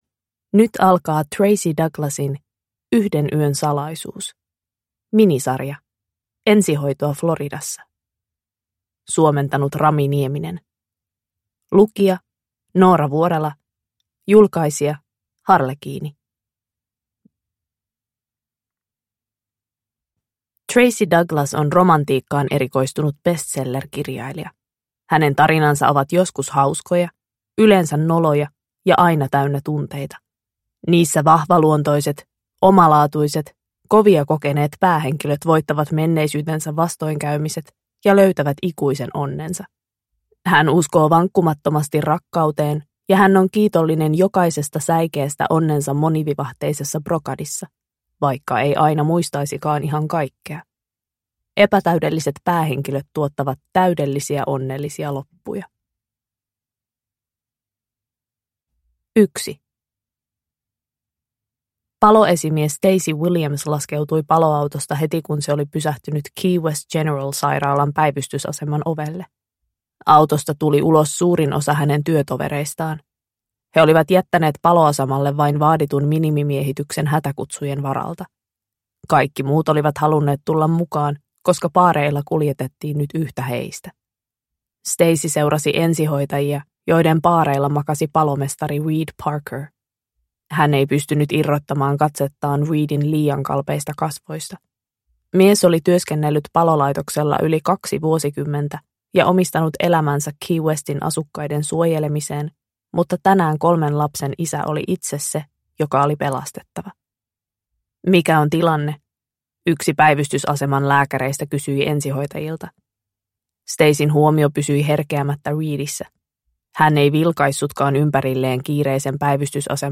Yhden yön salaisuus – Ljudbok